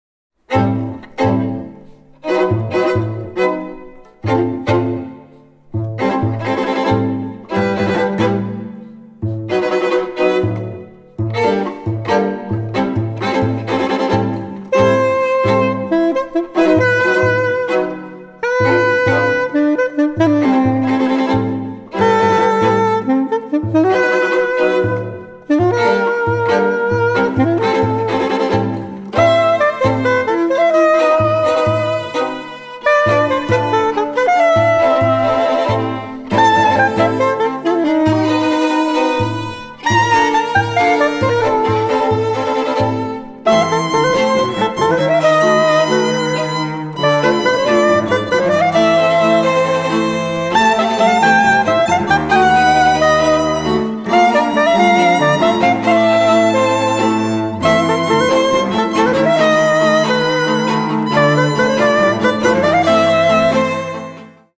sax soprano, sax baritono, quena e moxe�o
violino
viola
violoncello